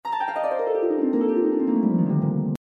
harp.mp3